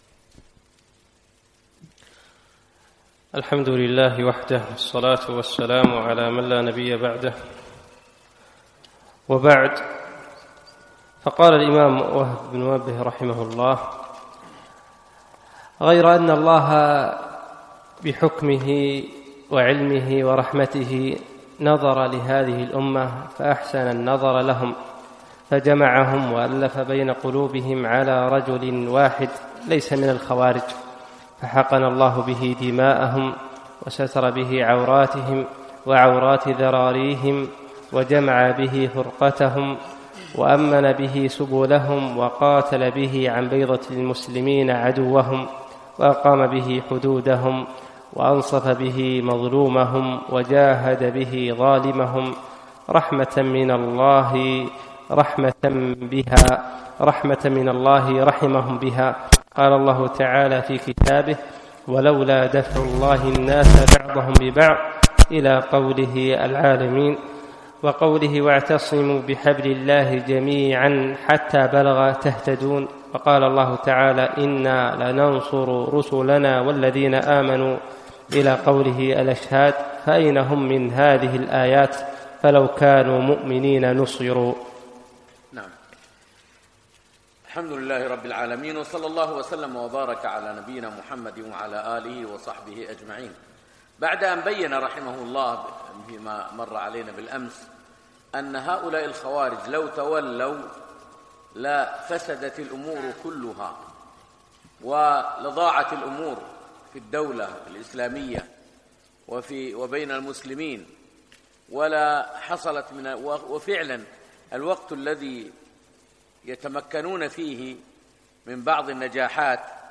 فجر الأحد 2 4 2017 مسجد صالح الكندري صباح السالم